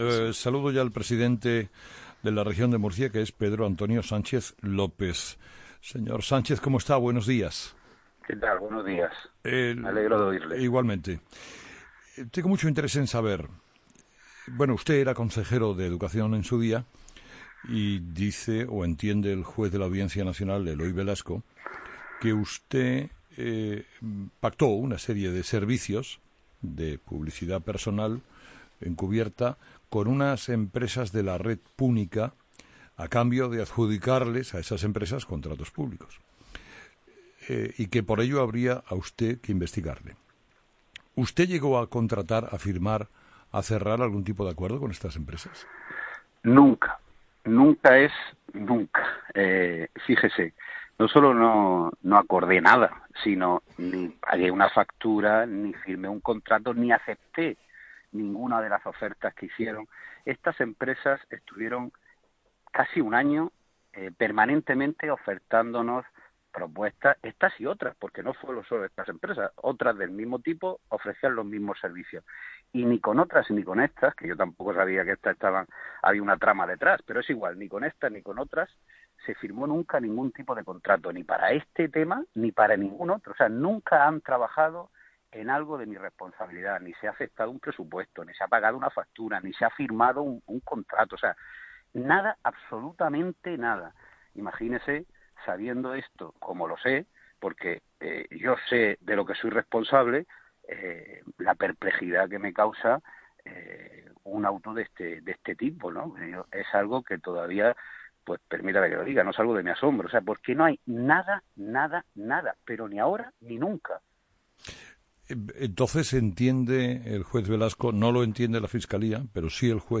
Entrevista a Pedro Antonio Sánchez...
Entrevistado: "Pedro Antonio Sánchez López"